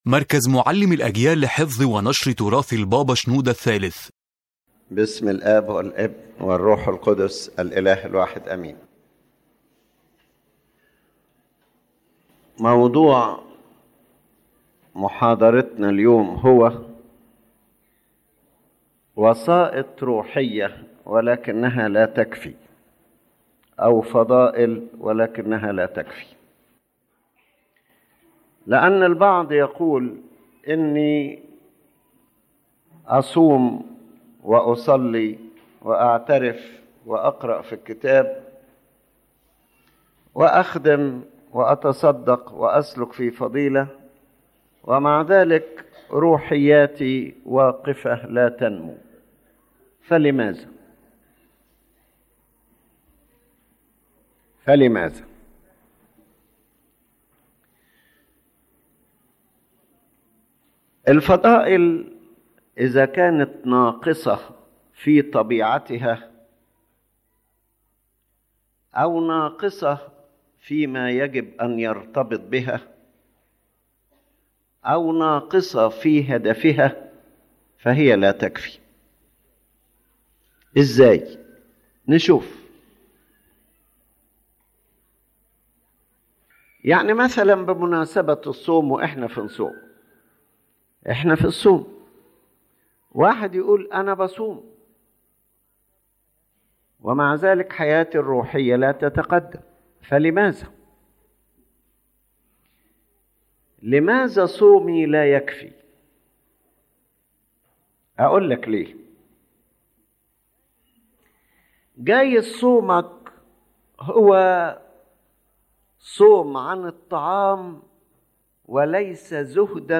The lecture explains that practicing spiritual virtues alone in a superficial or incomplete way is not enough for spiritual growth, because the true value of virtue depends on its inner depth and its connection to the correct spiritual purpose.